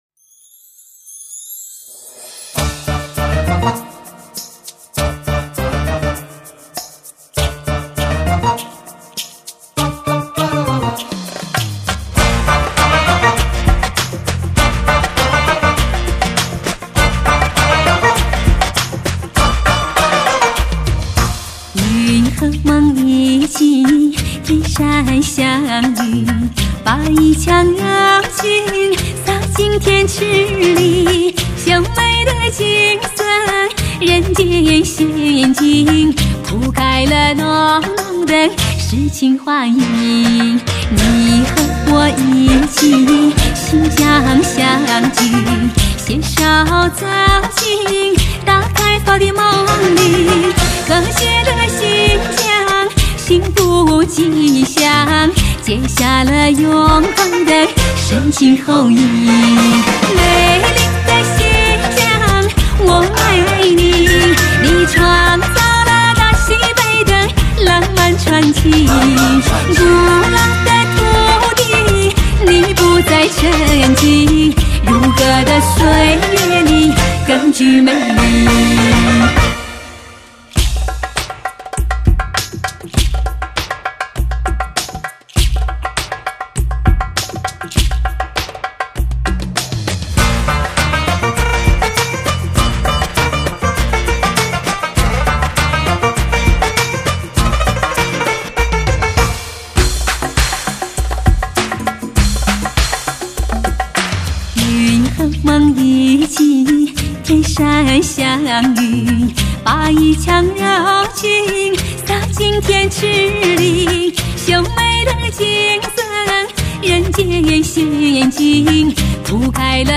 唱片类型：民族声乐